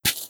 GrassStep4.wav